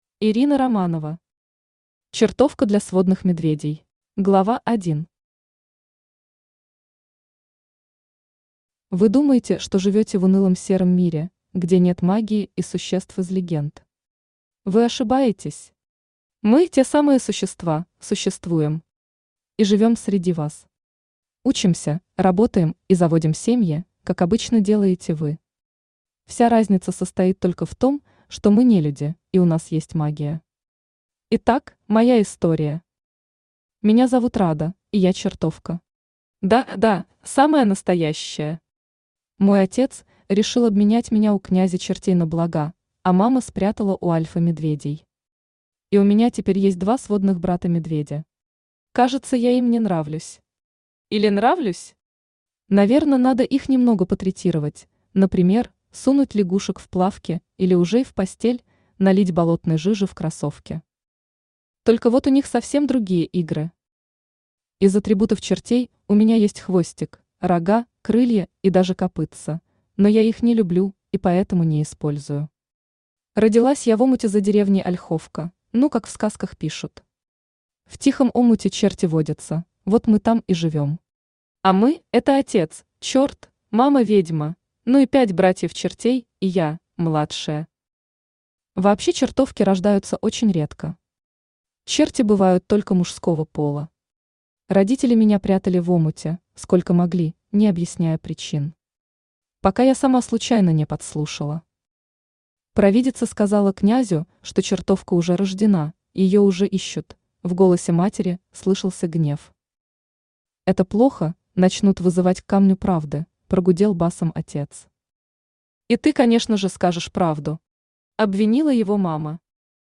Аудиокнига Чертовка для сводных медведей | Библиотека аудиокниг
Aудиокнига Чертовка для сводных медведей Автор Ирина Романова Читает аудиокнигу Авточтец ЛитРес.